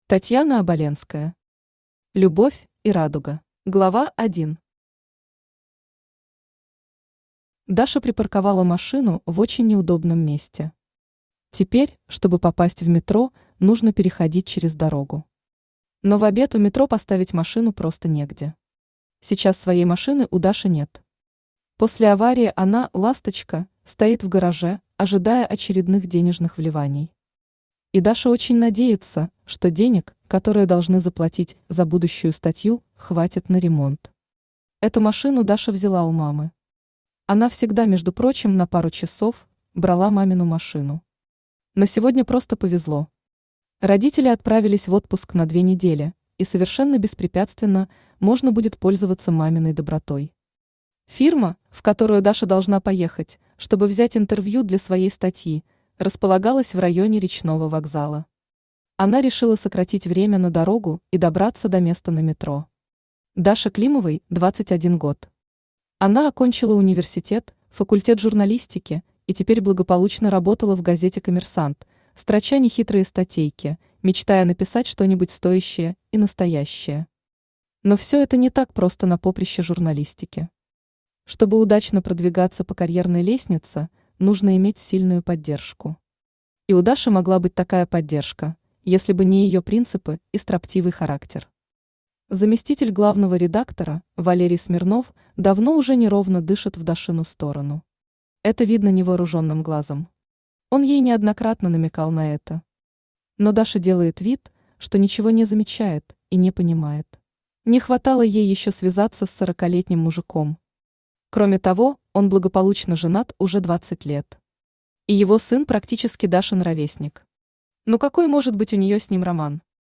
Аудиокнига Любовь и радуга | Библиотека аудиокниг
Aудиокнига Любовь и радуга Автор Татьяна Оболенская Читает аудиокнигу Авточтец ЛитРес.